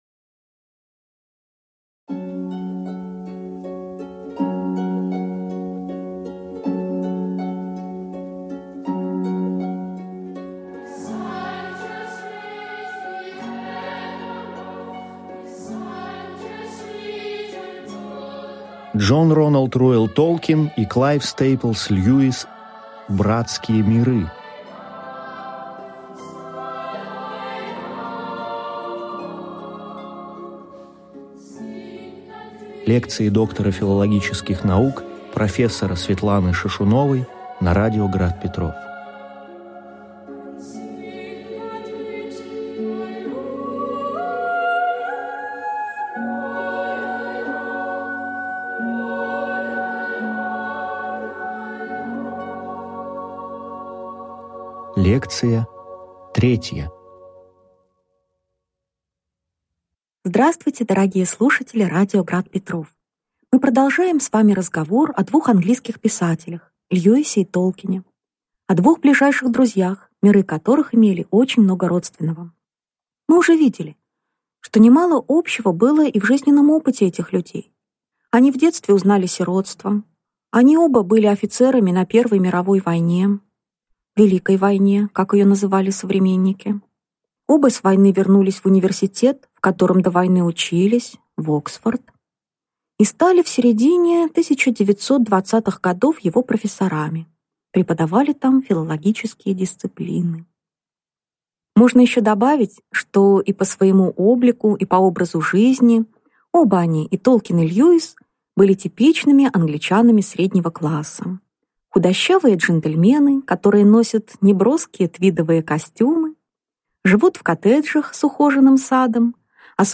Аудиокнига Лекция 3. Духовная биография К.С.Льюиса | Библиотека аудиокниг